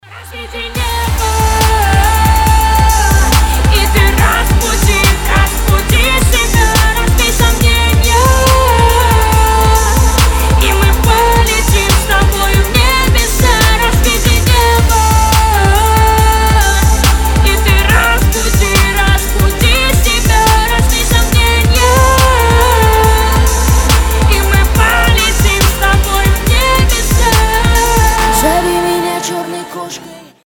поп
женский вокал
RnB